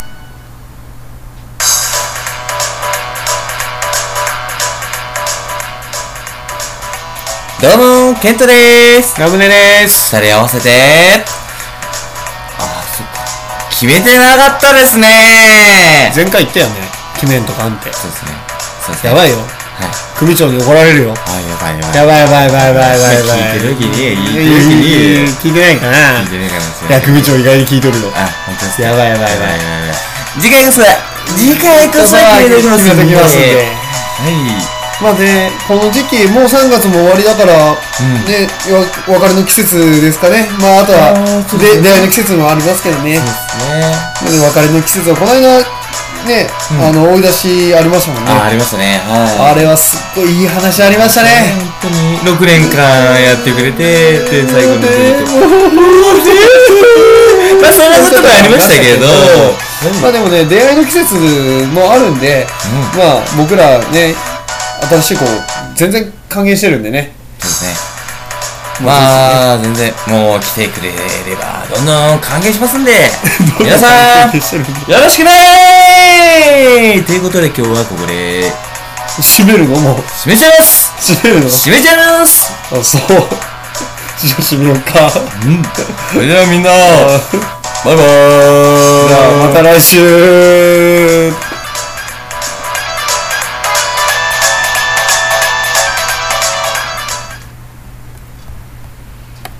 （音量が大きめなのでご注意ください！！）